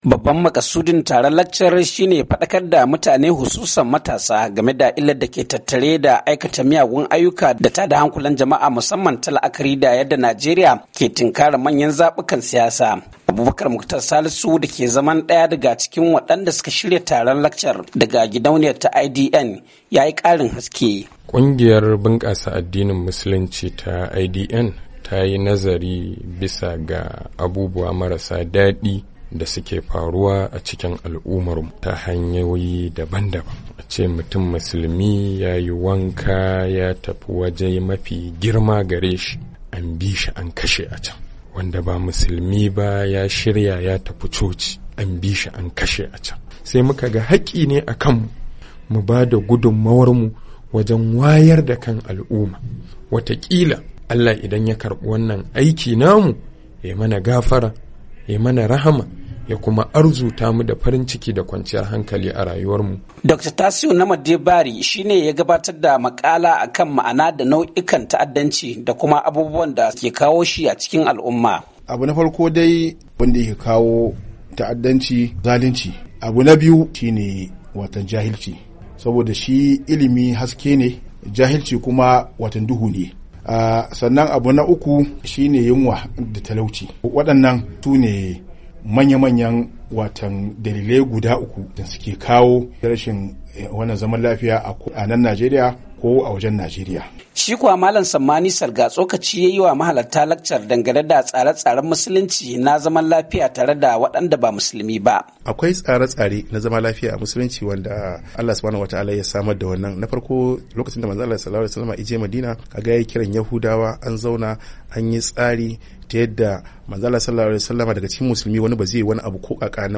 Wasu da suka halarci taron sun nuna gamsuwarsu da abubuwan da suka koya.